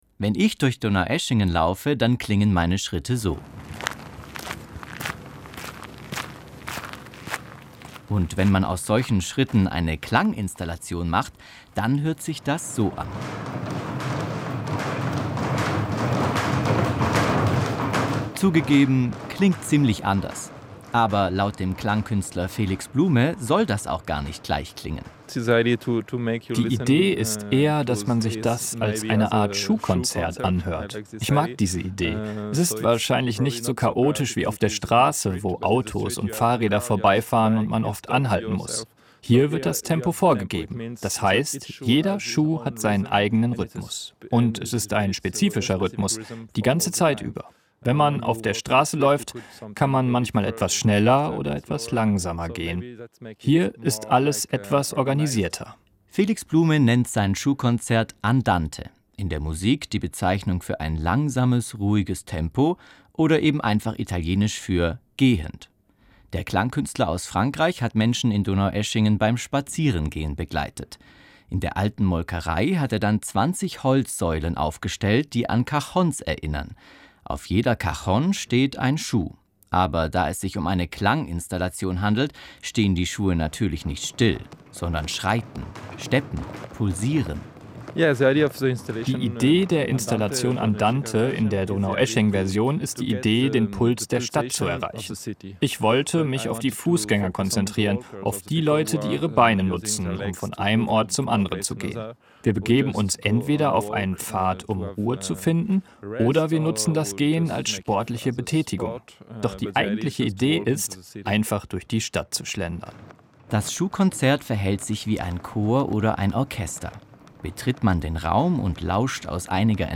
Bei den Donaueschinger Musiktagen gibt es neben Konzerten und Ausstellungen auch in diesem Jahr wieder Klanginstallationen: klingende Kunstwerke, die hör- und begehbar sind.
Jeder Schuh hat seinen eigenen spezifischen Rhythmus.
Das Schuhkonzert verhält sich wie ein Chor oder ein Orchester: Betritt man den Raum und lauscht aus einiger Entfernung, nimmt man das Klang-Gemisch wahr, die Verbindung aus allen Einzel-Klängen.